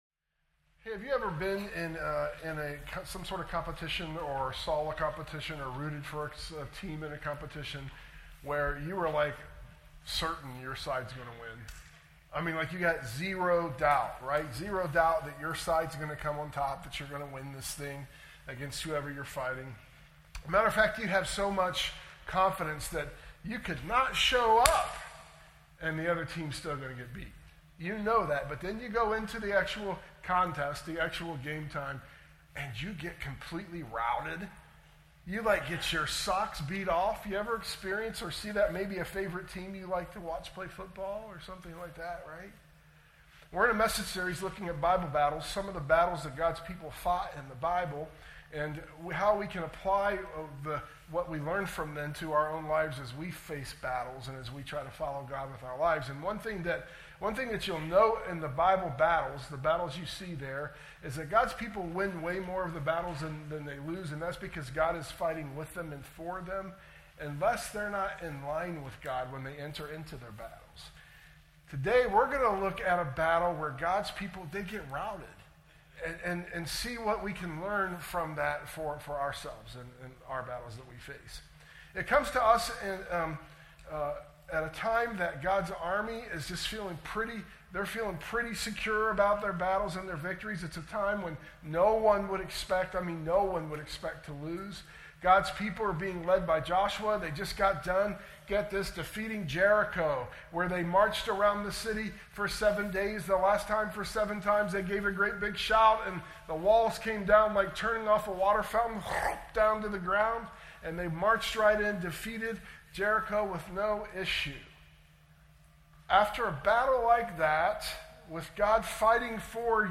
sermon_audio_mixdown_7_13_25.mp3